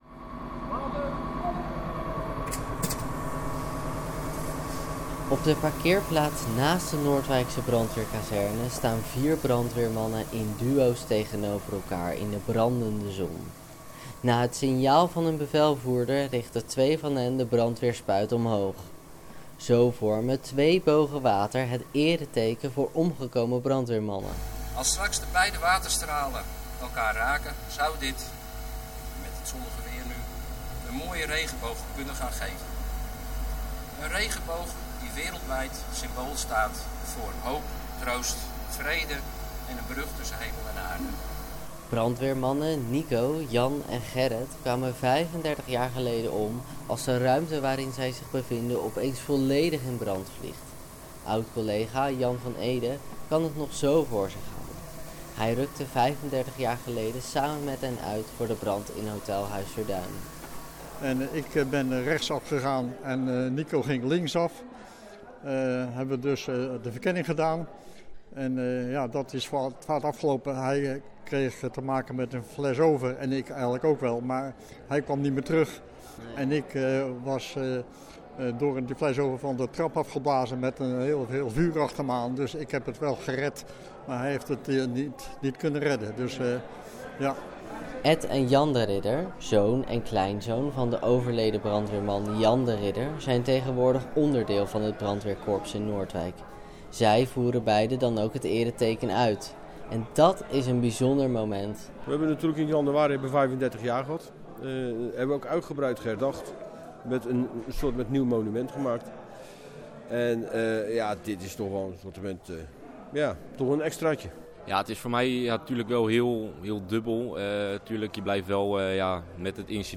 Ook burgemeester Verkleij sprak na de uitvoering van het ereteken op de Noordwijkse brandweerkazerne.